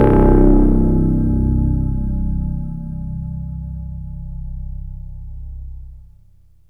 AMBIENT ATMOSPHERES-3 0006.wav